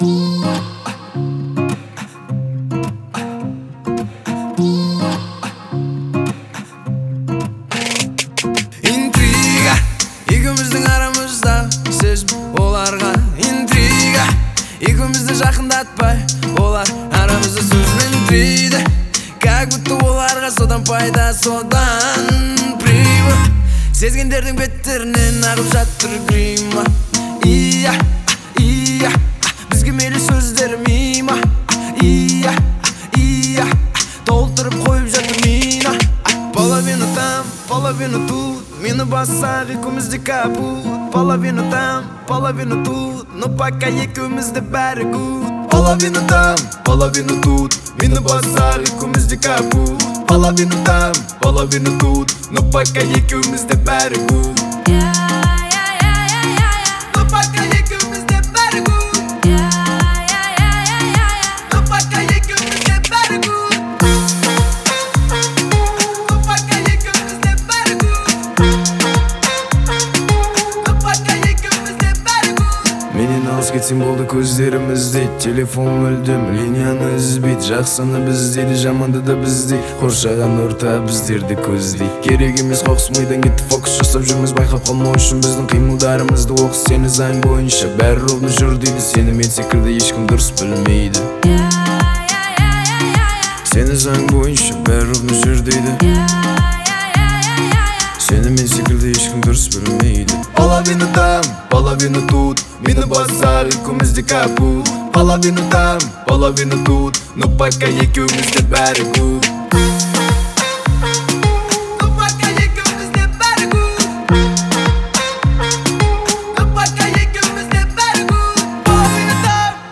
это яркая и зажигательная песня в жанре поп